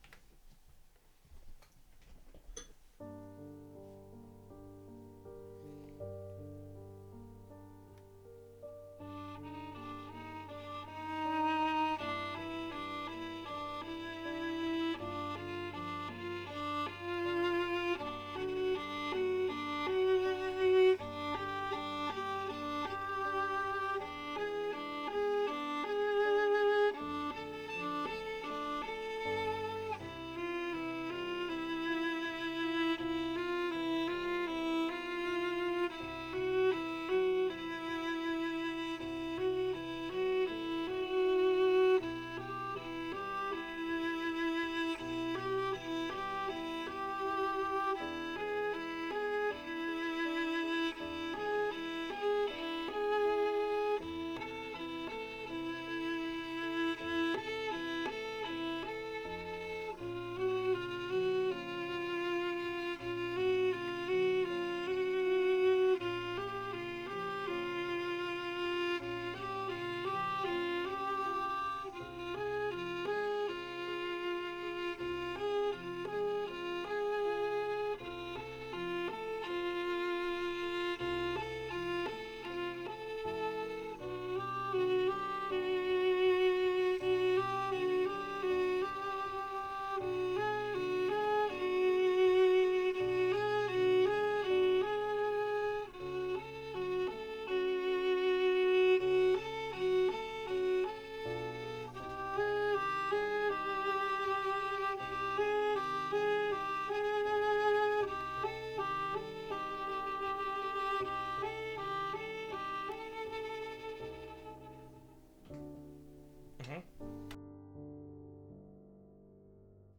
делаем легато у альта
Ну вот, записал сегодня парня на скорую руку.